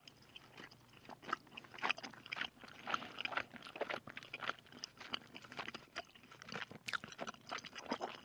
На этой странице собрана коллекция звуков жевания жвачки.
Звук жевания жвачки закрытым ртом